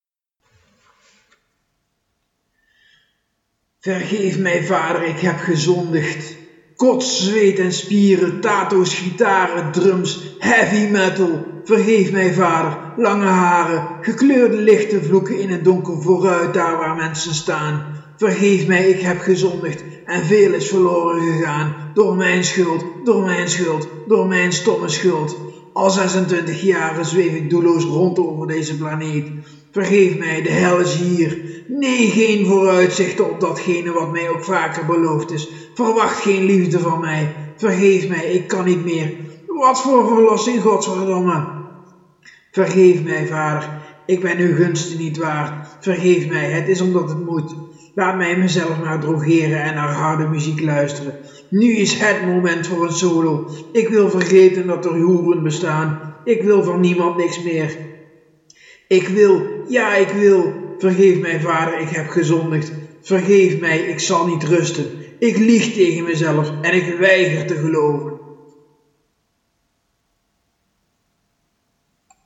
gesproken woorden…mp3